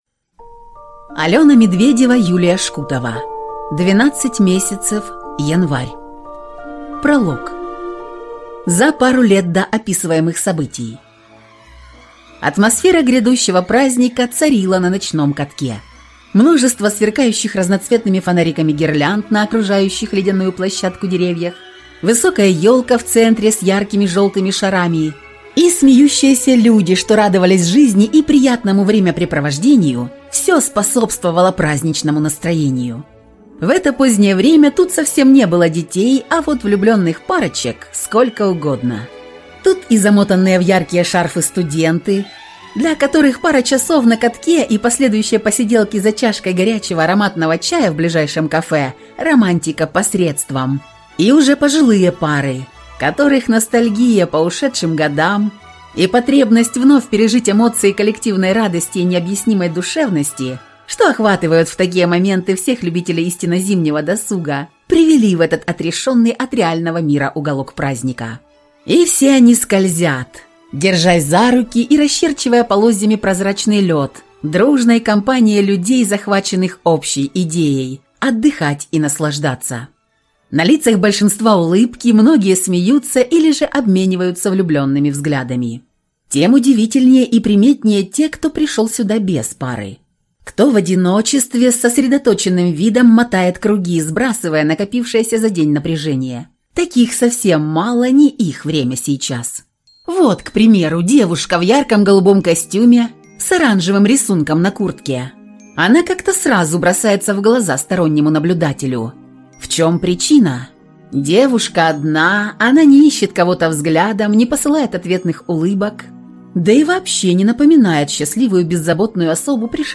Аудиокнига Двенадцать Месяцев. Январь | Библиотека аудиокниг